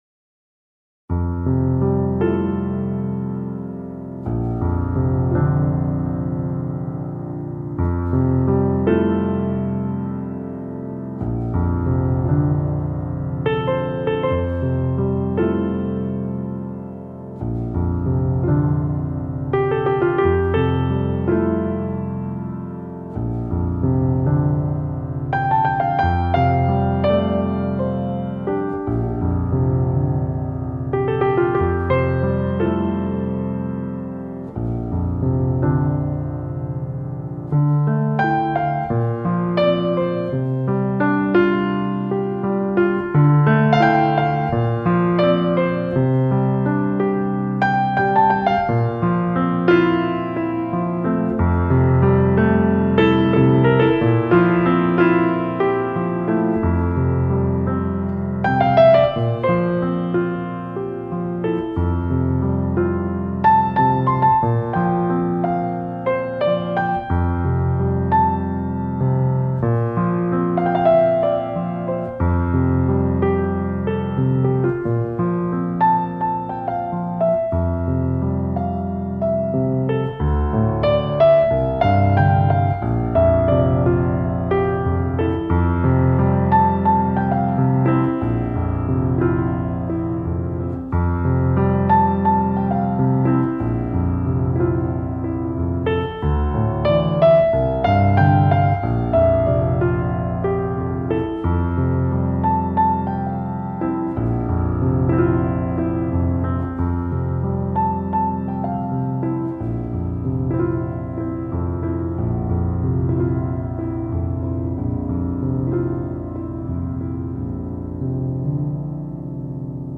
轻快的琶音和弦，展现落叶缤纷的唯美动感。